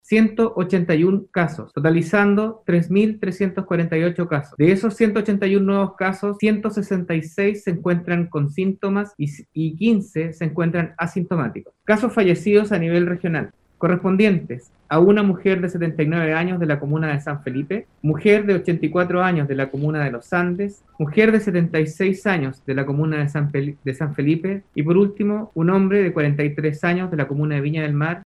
Así lo señaló el seremi subrogante de Salud, Jaime Jamett, quien además detalló que las muertes en las últimas 24 horas corresponden a tres mujer, de 66, 79 y 84 años, y un hombre de 43 años.